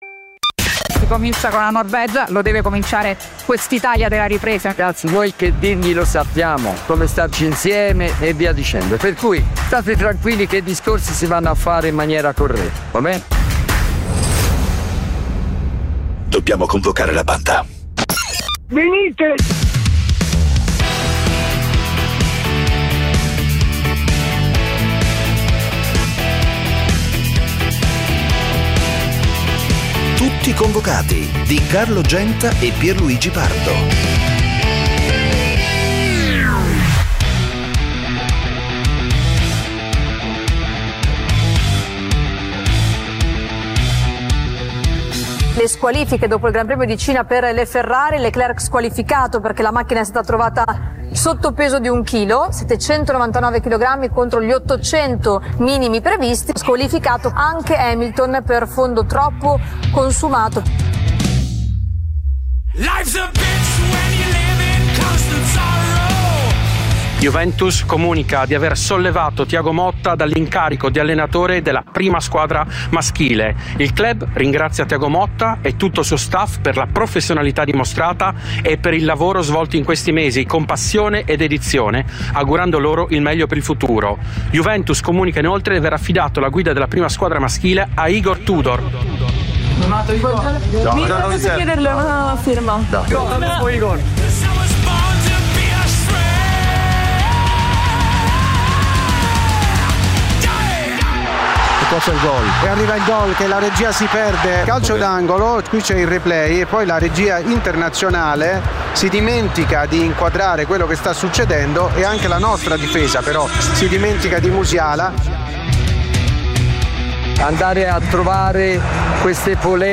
Le voci e i suoni del calcio. Una lettura ironica e coinvolgente degli avvenimenti dell'attualità sportiva, senza fanatismi e senza tecnicismi. Commenti, interviste e soprattutto il dibattito con gli ascoltatori, che sono Tutti convocati.
Con imitazioni, tic, smorfie, scherzi da spogliatoio e ironia irriverente. L'unico programma in diretta sul calcio a partite appena finite, in cui da casa o dallo stadio gli ascoltatori possono dire la loro perché sono sempre convocati.